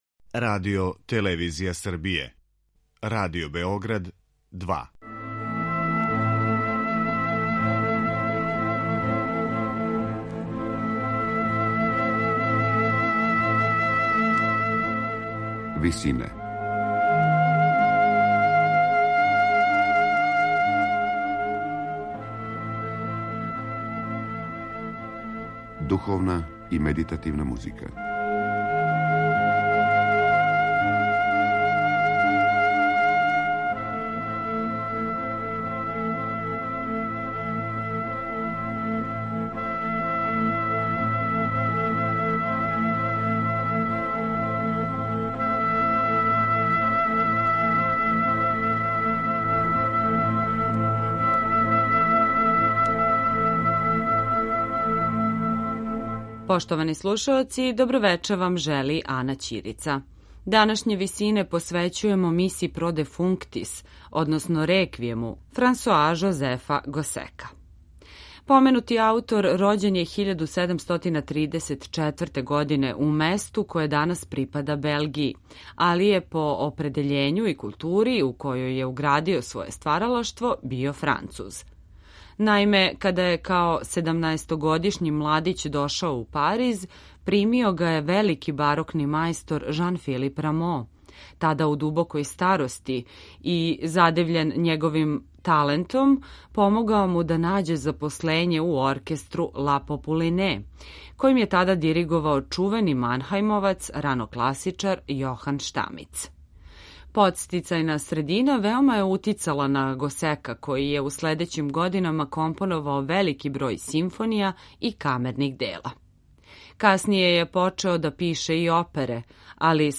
медитативне и духовне композиције аутора свих конфесија и епоха